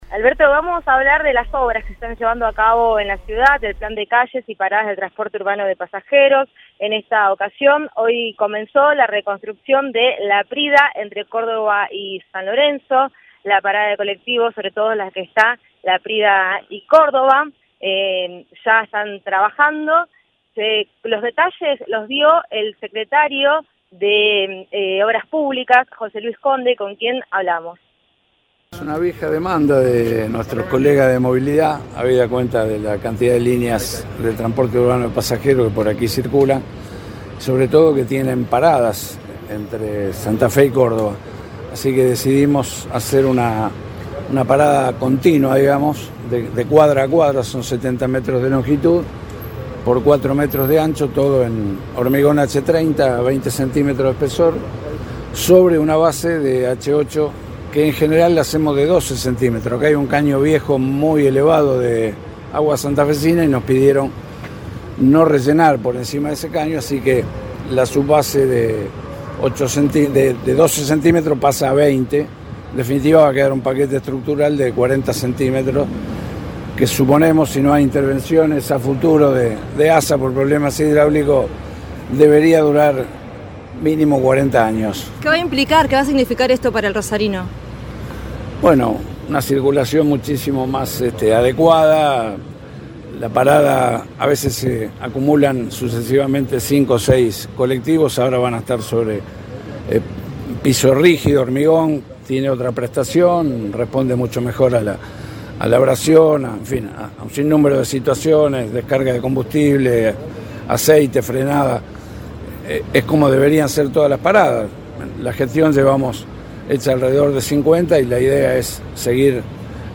José Luis Conde, secretario de Obras Públicas de la Municipalidad de Rosario, habló con el móvil de Cadena 3 Rosario, en Siempre Juntos, y explicó: “Es una vieja demanda de nuestros colegas de Movilidad, por la cantidad de colectivos que por la zona circulan. La intervención debería durar 40 años. Esto brindará una circulación más adecuada”